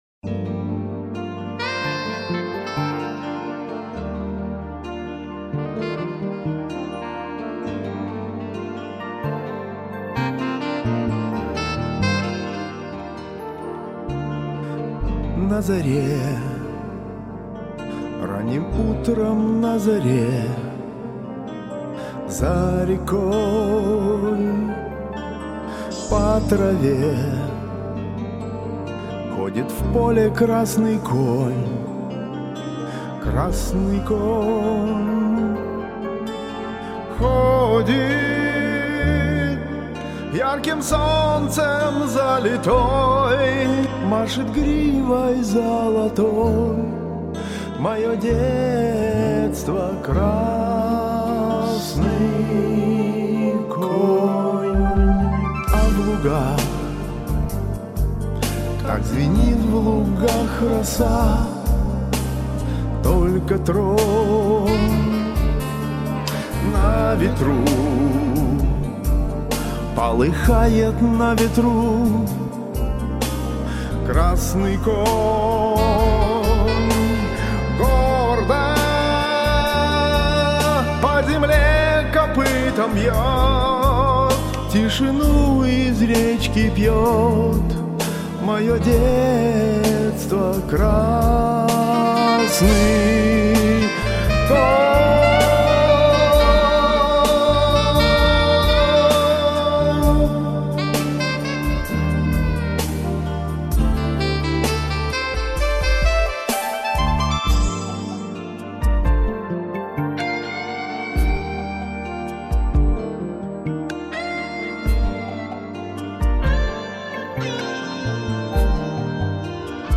Скорее инструментал, а я исполнил , как смог....
Считаю если вслушаться то кантилена у обоих прихрамывает.